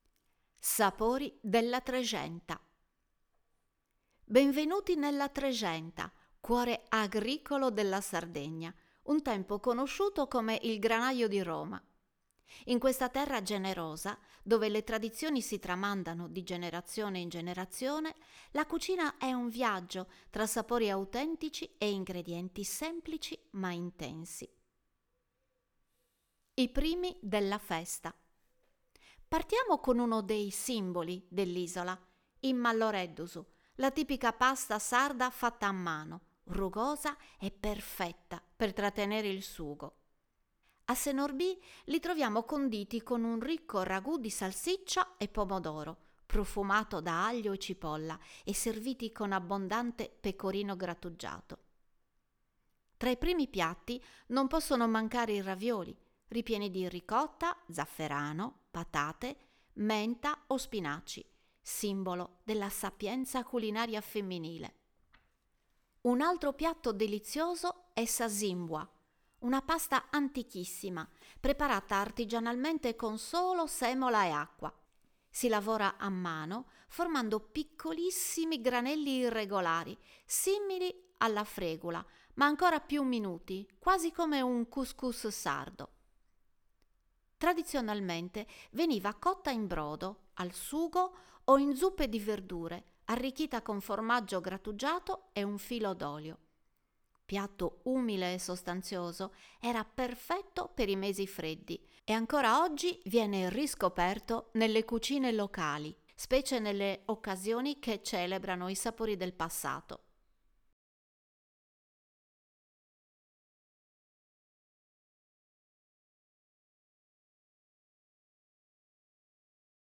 Voce Narrante
🎧 Audioguida - Sapori della Trexenta